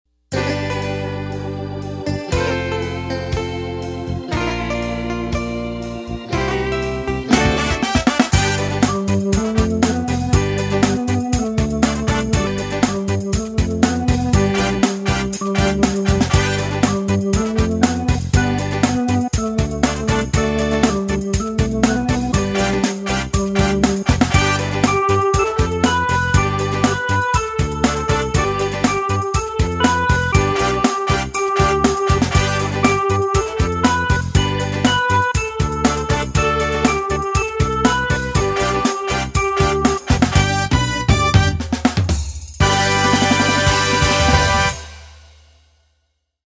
teclado